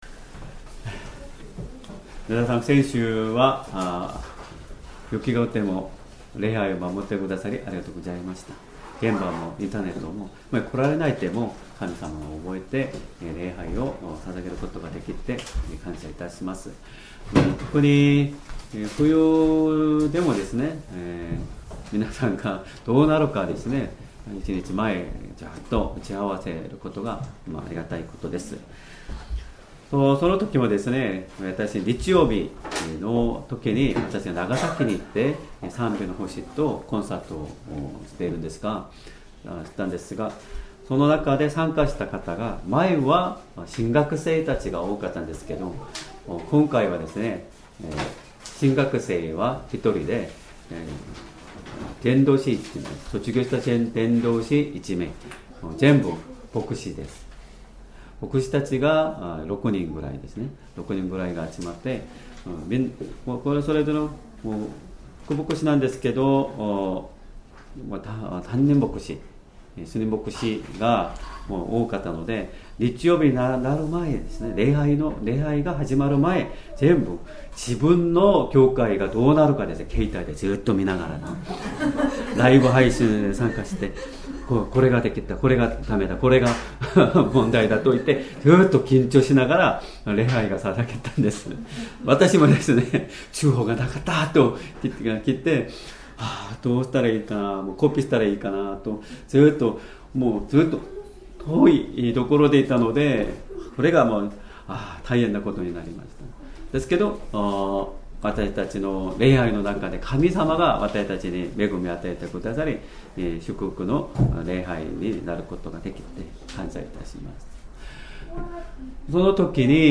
Sermon
2026年2月15日 主日礼拝 説教 「イエスの名によって求める祈り」 聖書 マルコの福音書 2章 13-17節 2:13 イエスはまた湖のほとりへ出て行かれた。